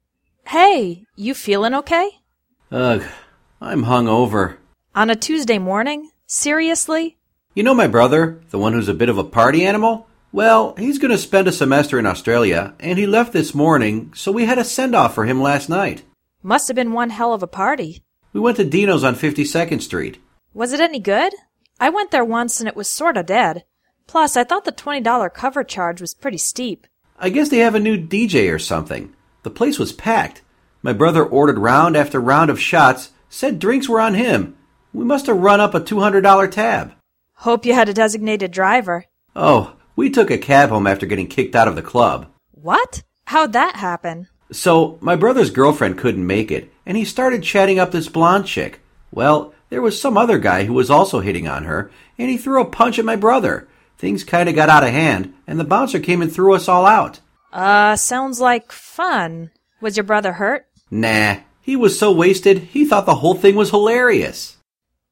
party-dialog.mp3